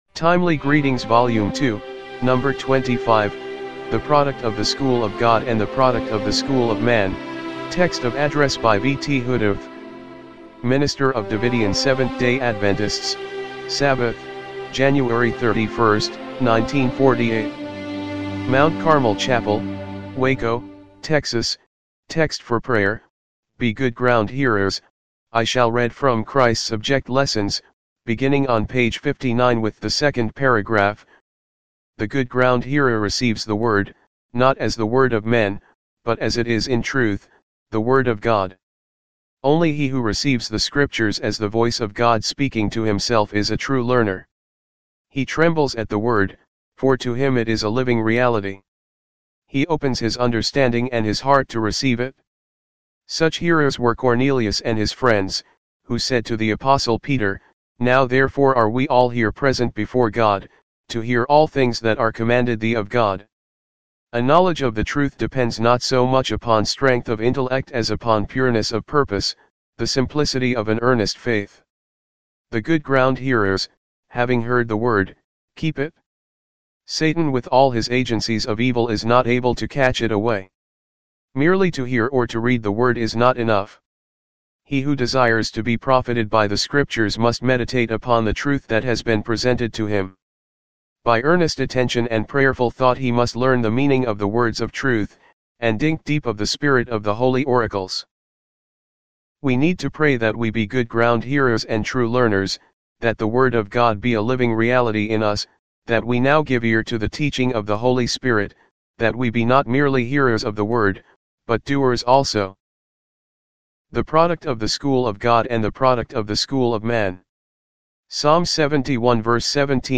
timely-greetings-volume-2-no.-25-mono-mp3.mp3